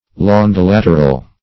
Search Result for " longilateral" : The Collaborative International Dictionary of English v.0.48: Longilateral \Lon`gi*lat"er*al\, a. [L. longus long + lateralis lateral, fr. latus side.] Having long sides; especially, having the form of a long parallelogram.